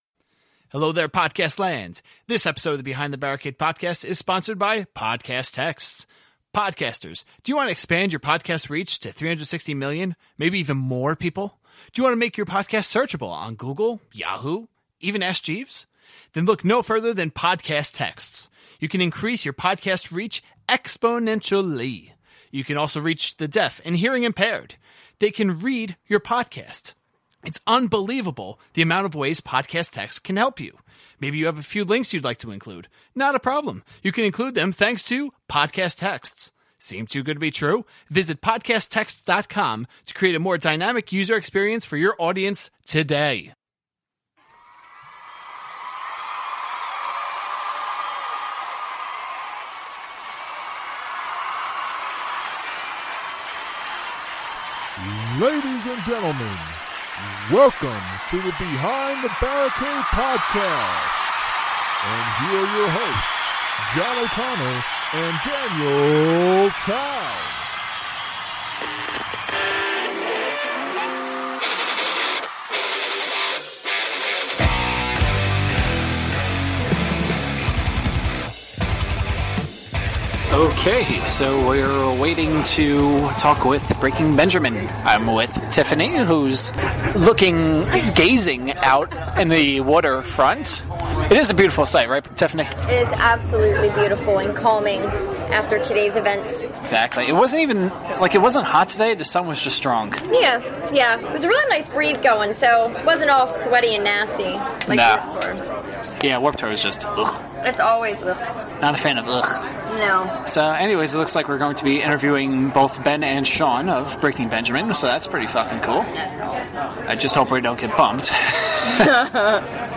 Episode 53 features our first set of interviews from Rock Allegiance 2016! In the first interview, we talk with Aaron, Ben and Shaun of Breaking Benjamin. The second interview features Paolo Gregoletto, the bassist of Trivium!